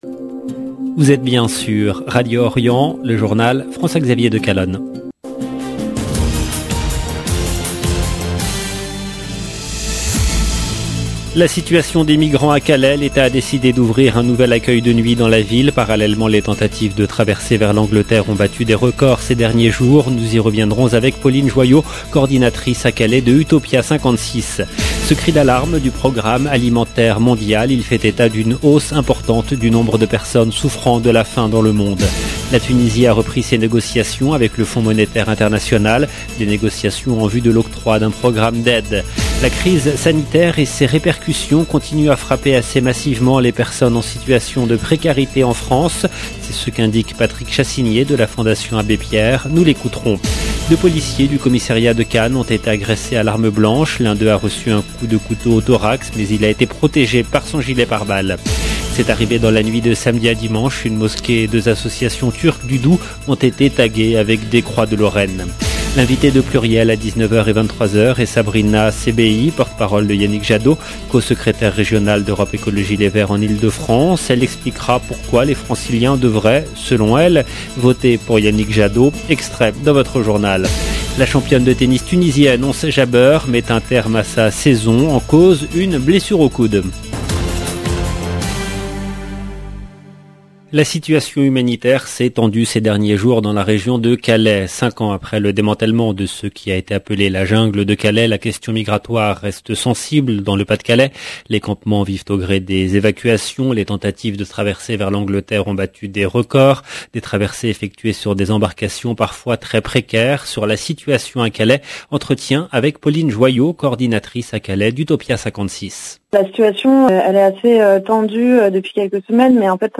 LE JOURNAL EN LANGUE FRANCAISE DU SOIR DU 8/11/21